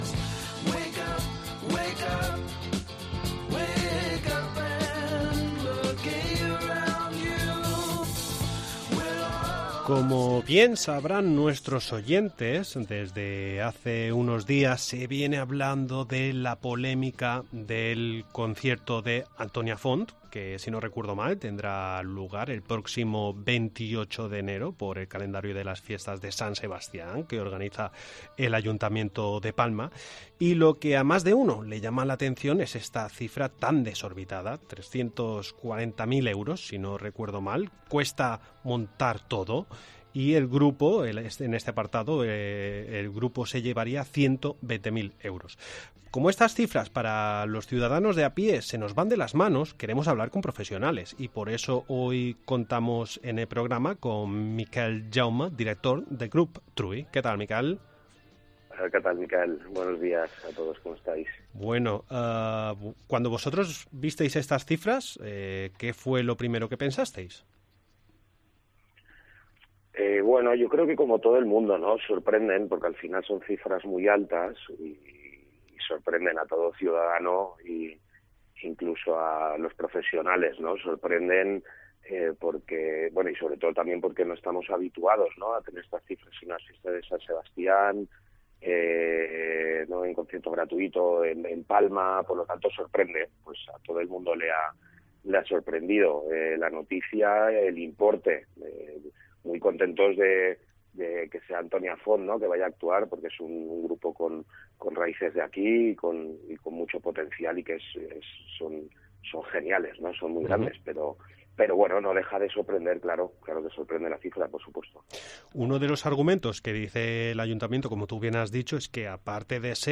atiende a los micrófonos de COPE para dar su opinión sobre el concierto de Antonia Font desde un punto de vista profesional y del gremio.